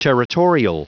Prononciation du mot territorial en anglais (fichier audio)
Prononciation du mot : territorial